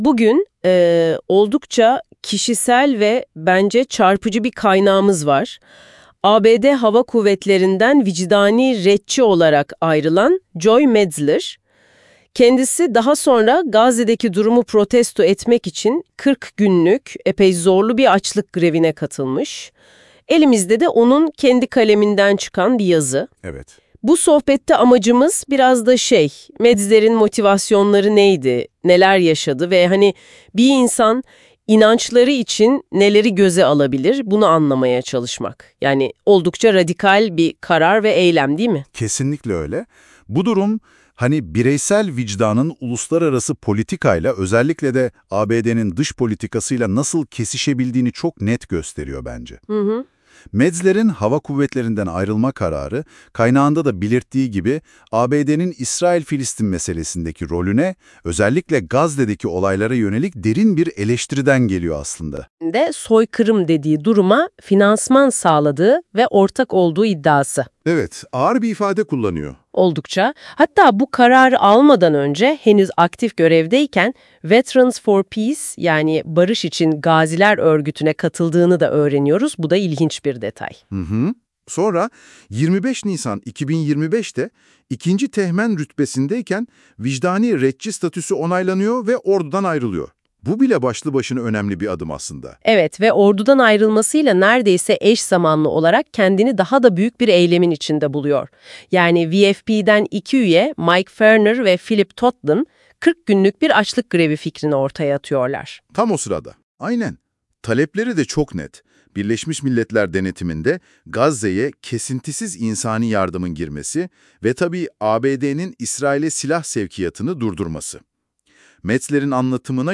Makalenin sesli analizi: